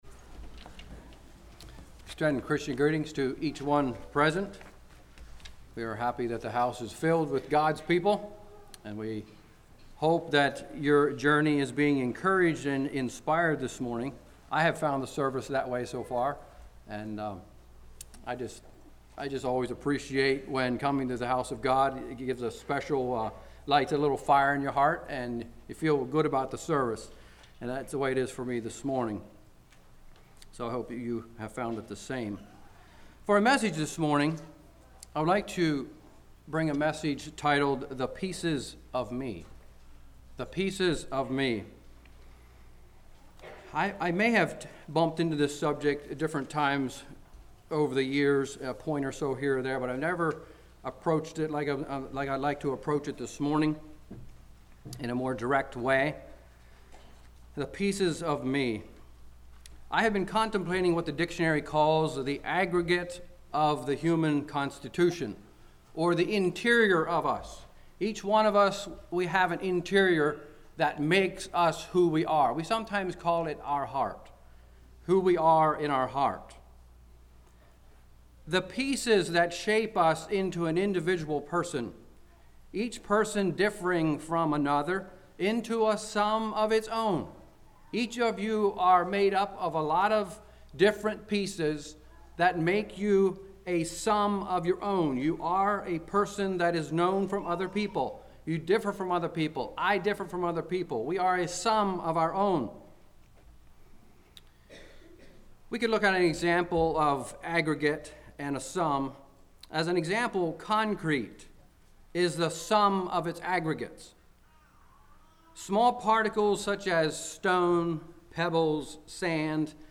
58:51 Summary: Teaching on how to deal with the broken pieces of life Categories